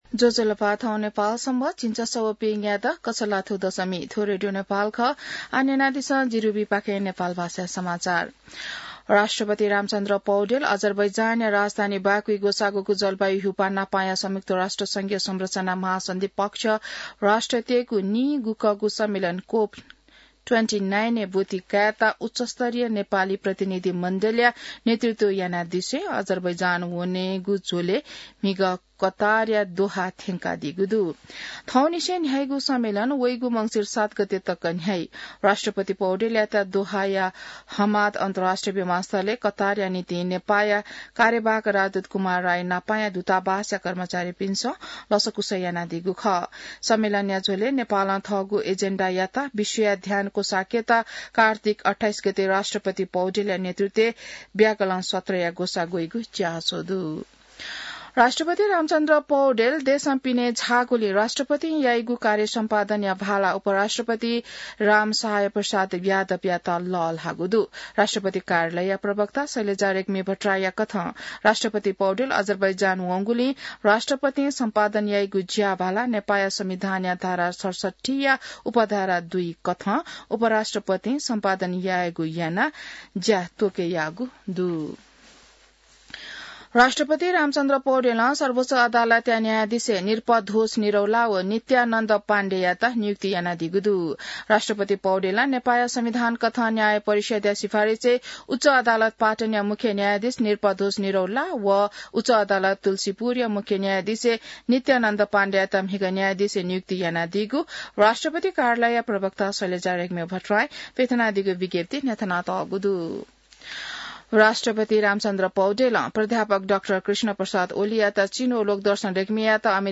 नेपाल भाषामा समाचार : २७ कार्तिक , २०८१